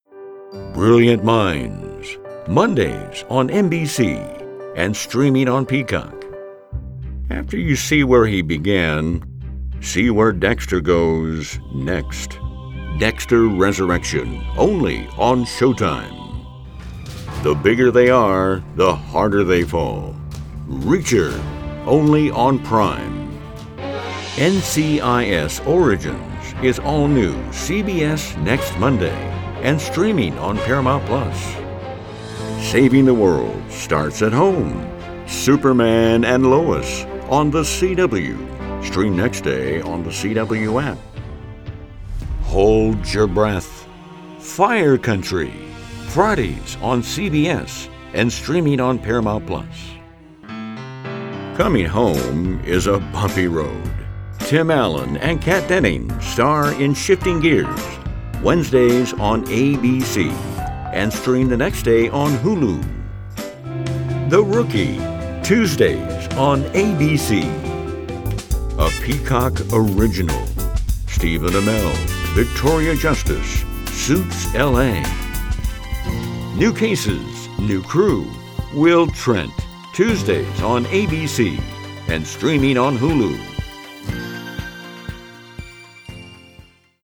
southern
Senior